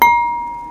cup ding kitchen mallet sound effect free sound royalty free Sound Effects